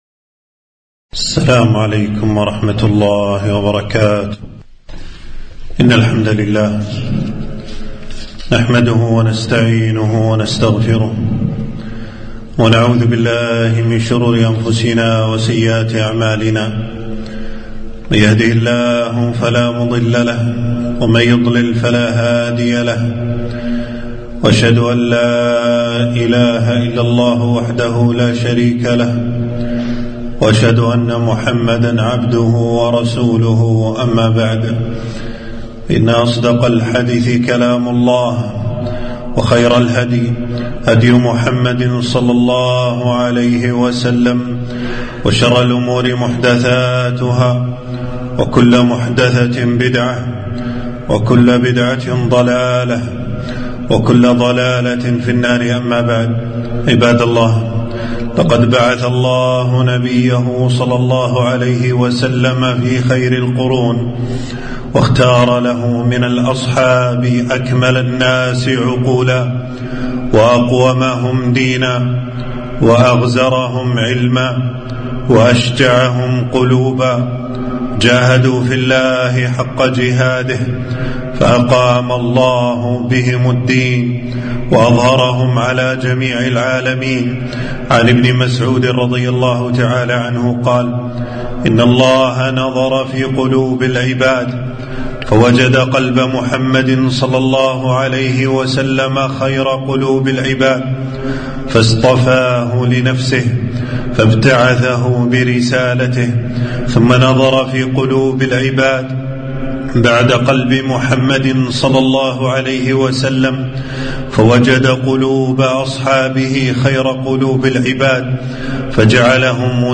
خطبة - فضل الصحابة عموما والعشرة المبشرون بالجنة خصوصًا